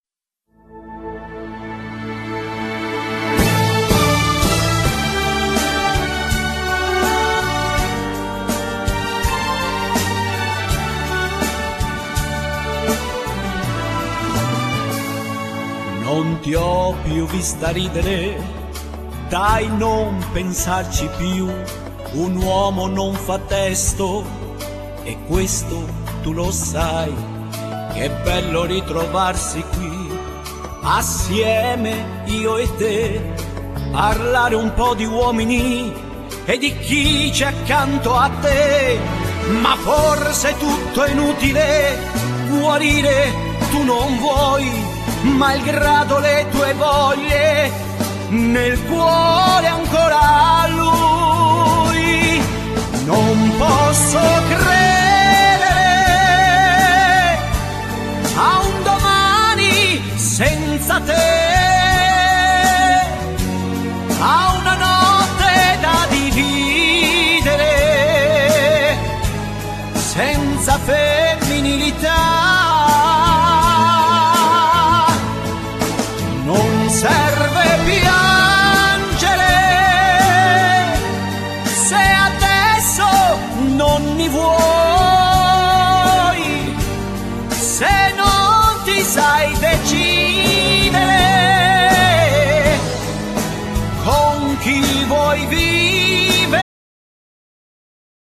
Genere : Pop / Folk